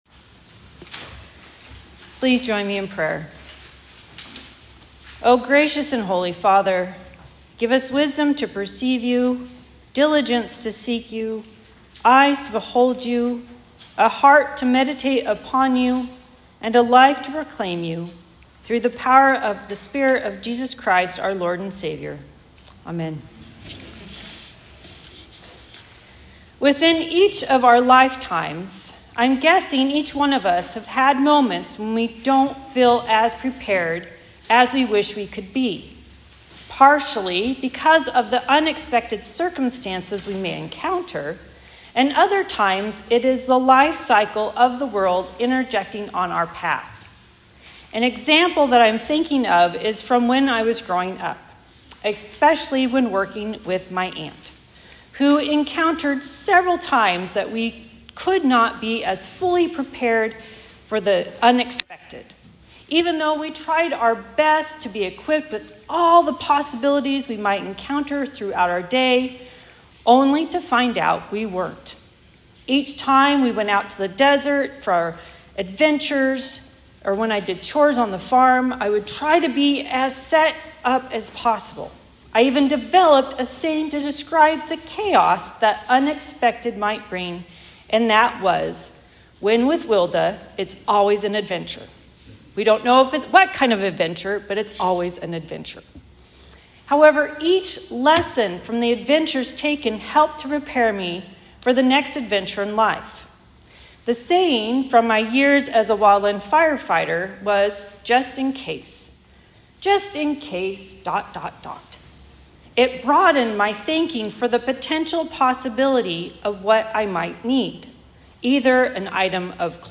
Sermons | Fir-Conway Lutheran Church